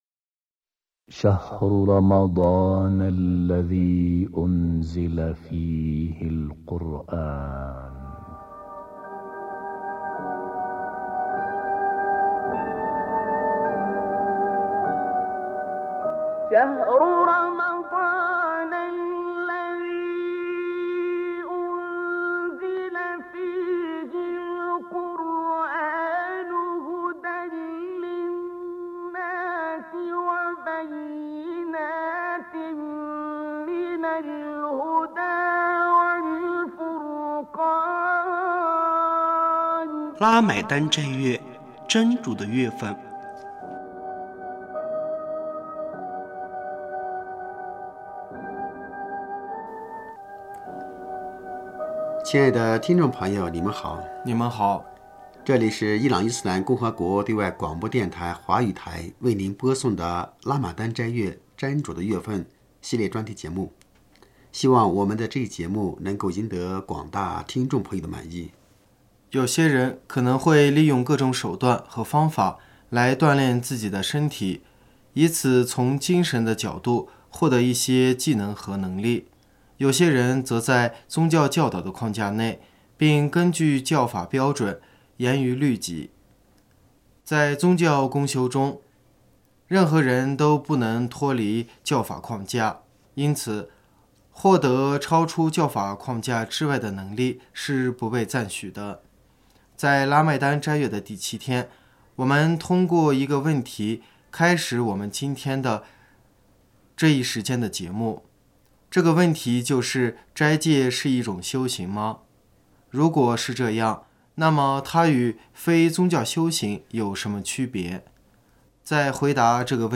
这里是伊朗伊斯兰共和国对外广播电台华语台为您播送的拉麦丹斋月——真主的月份专题节目。